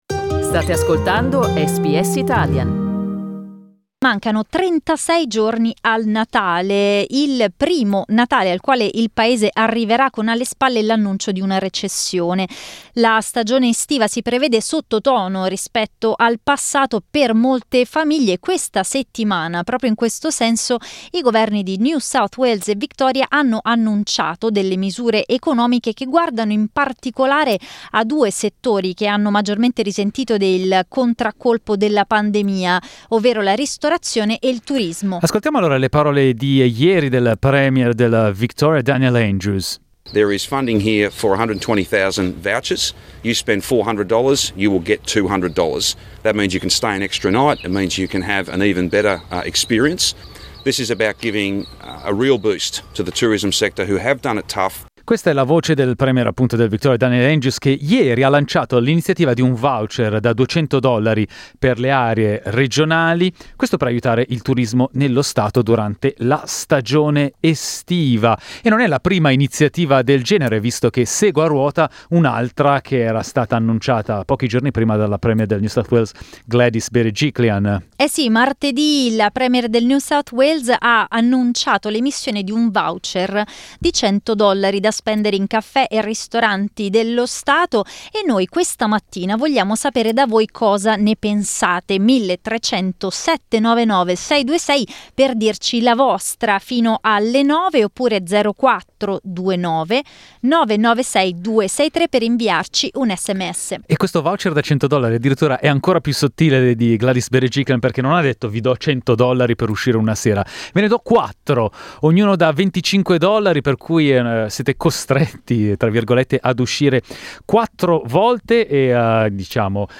Oggi abbiamo aperto le nostre linee per raccogliere reazioni immediate dei nostri ascoltatori.